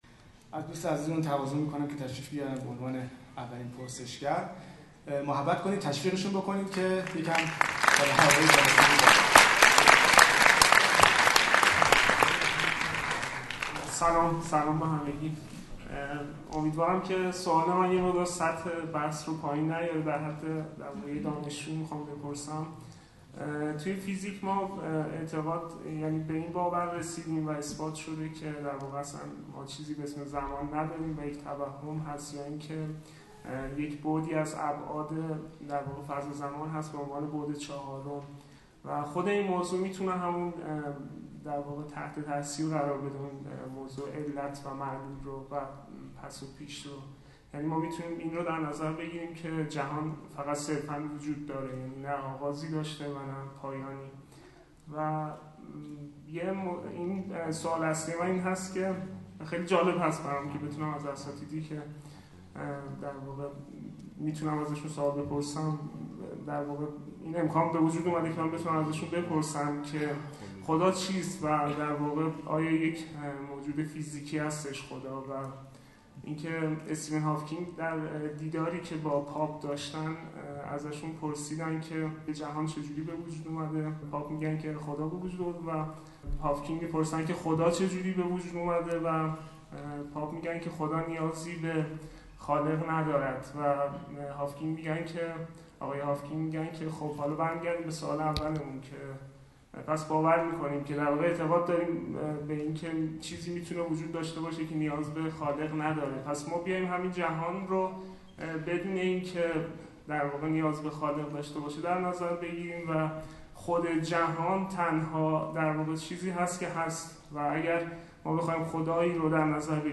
فایل جلسه تریبون آزاد دانشجویی با عنوان بررسی چالش‌های علم فیزیک جدید و انکار خدا توسط فیزیک‌دانان آتئیست
تریبون-آزاد-دانشجویی-با-موضوع-الحاد-جدید.mp3